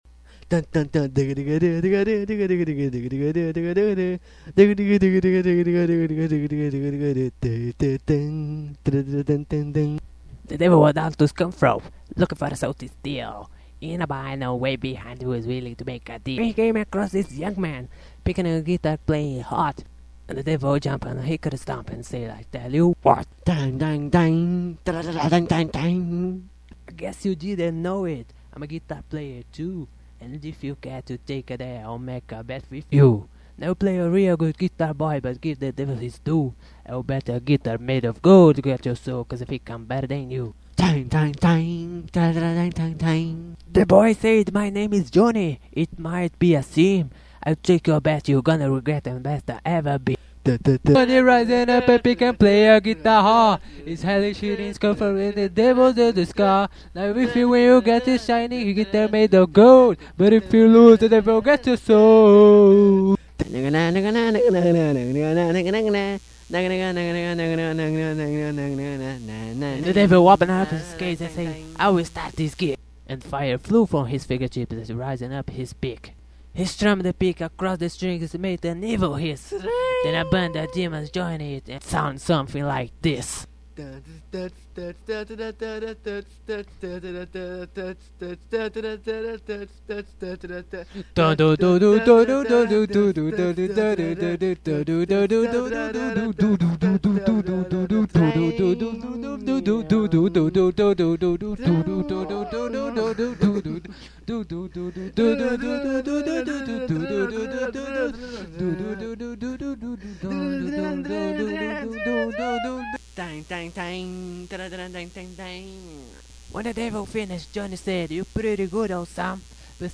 Piano
Guitar